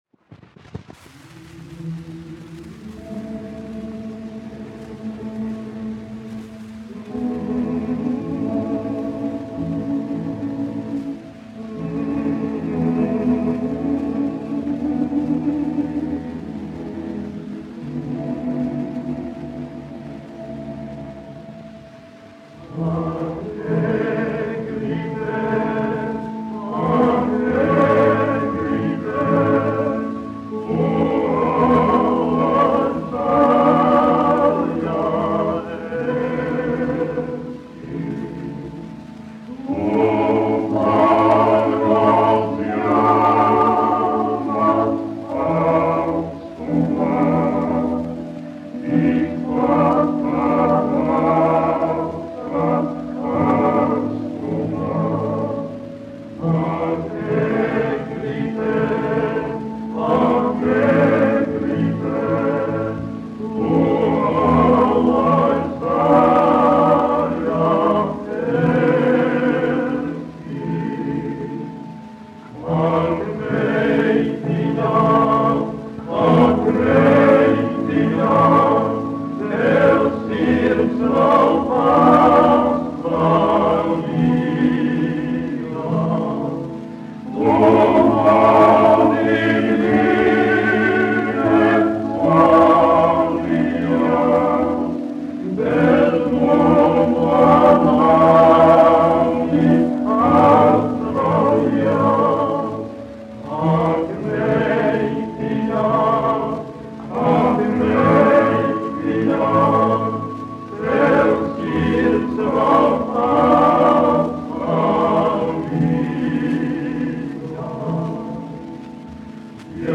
1 skpl. : analogs, 78 apgr/min, mono ; 25 cm
Ziemassvētku mūzika
Latvijas vēsturiskie šellaka skaņuplašu ieraksti (Kolekcija)